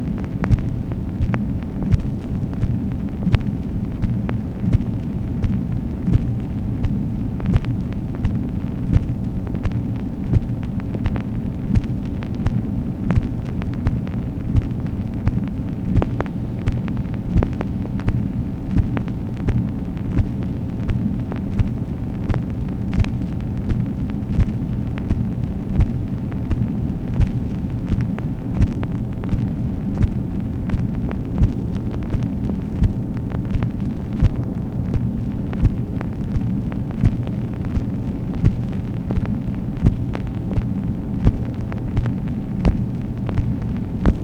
MACHINE NOISE, March 2, 1966
Secret White House Tapes | Lyndon B. Johnson Presidency